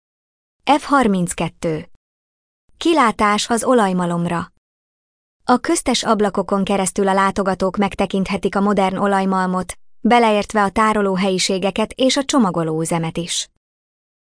Hangalapú idegenvezetés